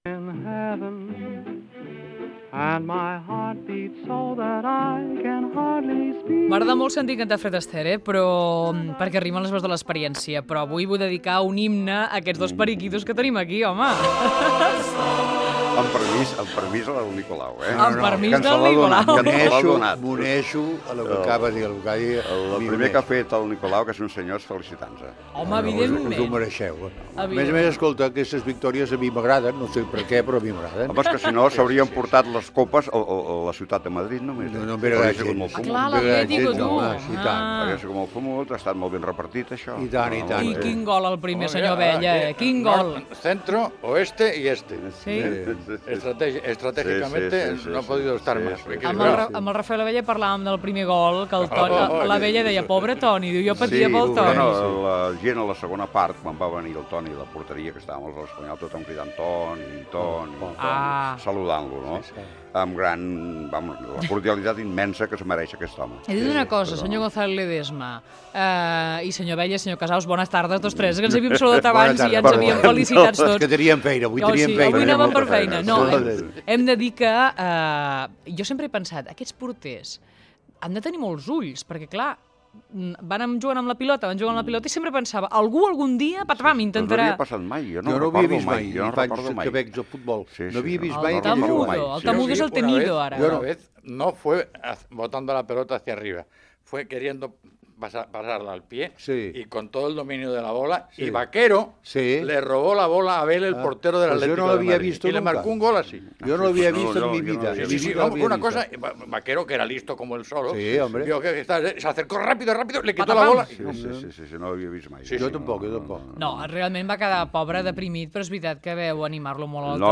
Fragment de la tertúlia "Les veus de la experiència" amb Nicolau Casaus, Rafael Abella i Francisco González Ledesma.
Entreteniment